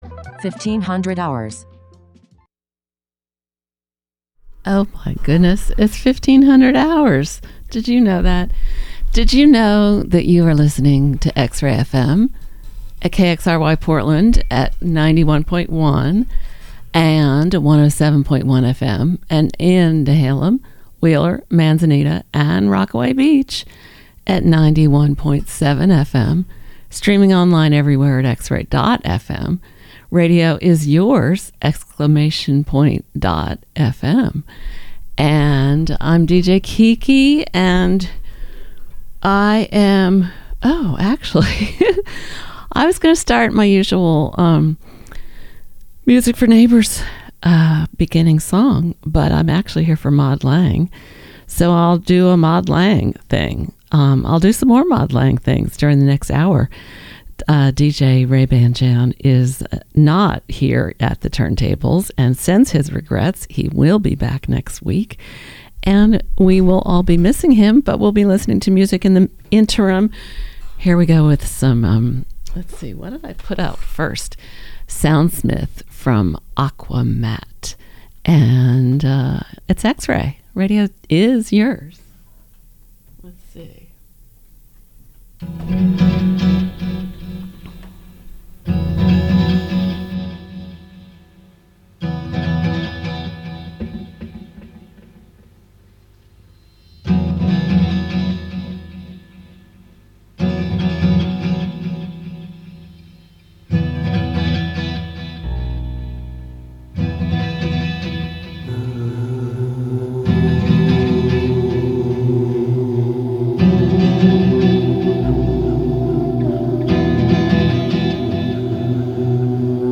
All manner of guitar oriented pop/punk/rock/soul/garage with a local emphasis served here!